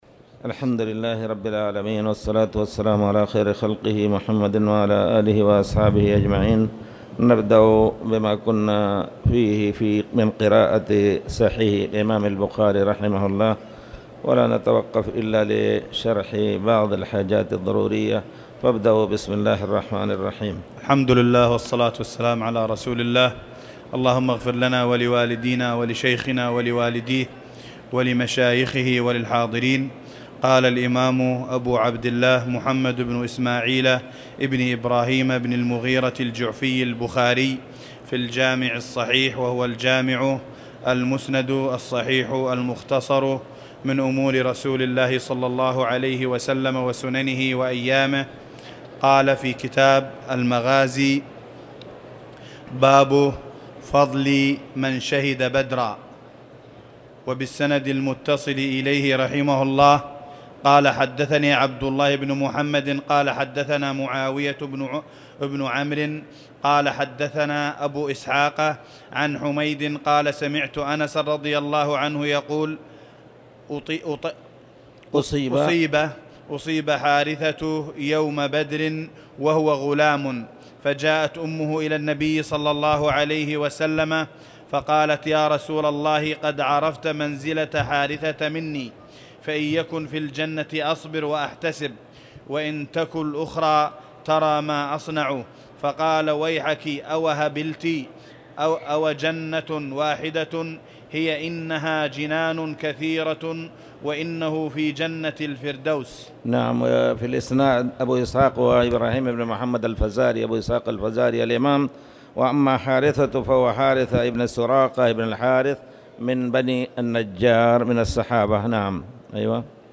تاريخ النشر ١١ شوال ١٤٣٧ هـ المكان: المسجد الحرام الشيخ